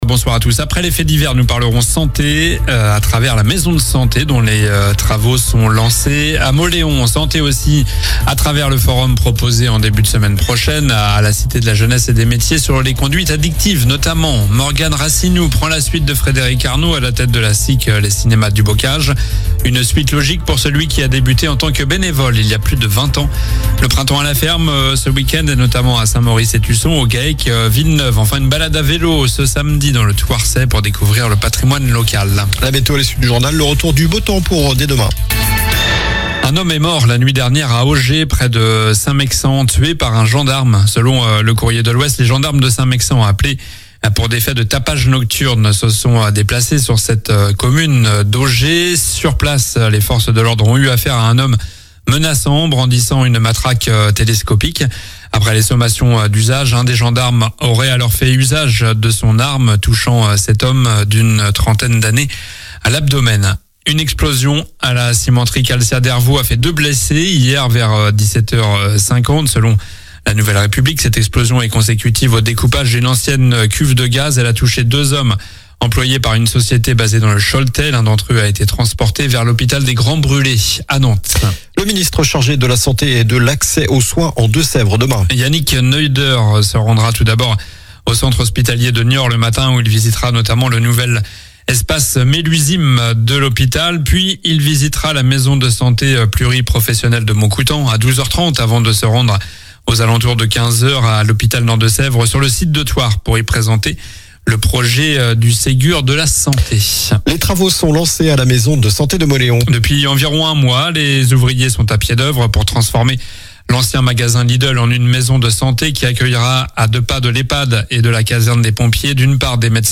Journal du mercredi 21 mai (soir)